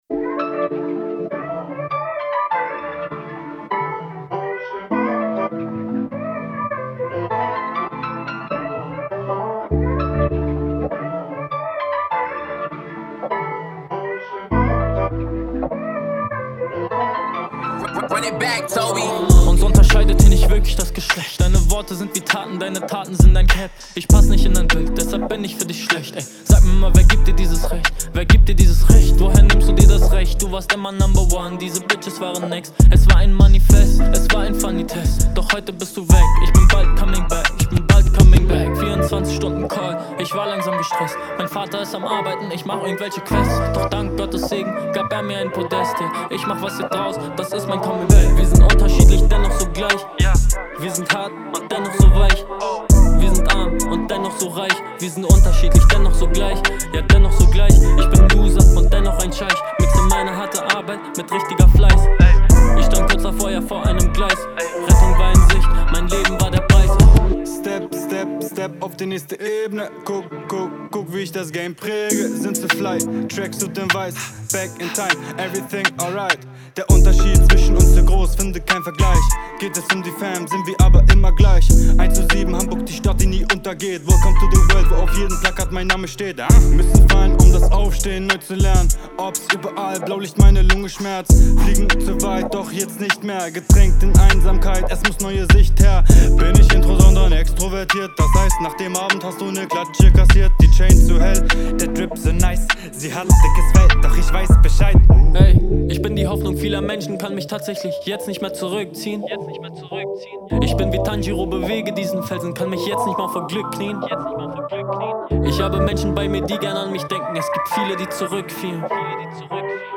Hier, im Keller des Jugendclubs „Blechkiste“ in Harburg, können junge Menschen eigene Songs aufnehmen.